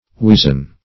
Weazen \Wea"zen\, a. [See Wizen.]